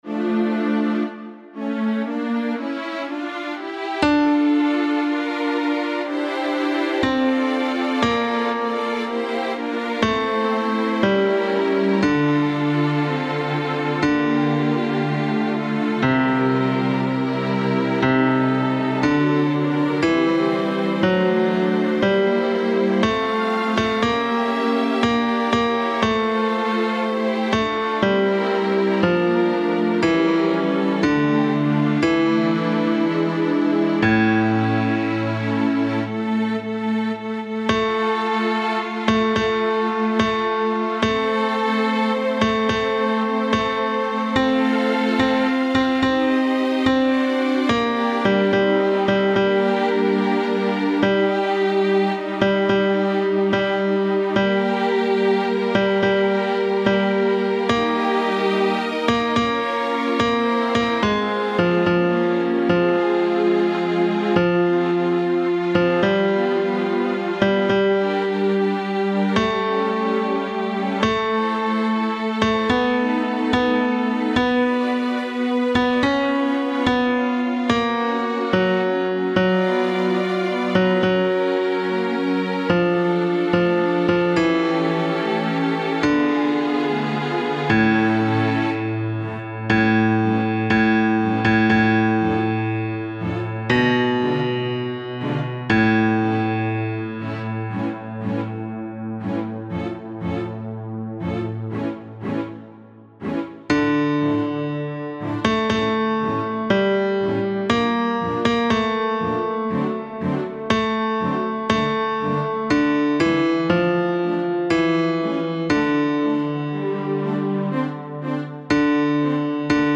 Bajo II
6.-Libera-me-BAJO-II-MUSICA.mp3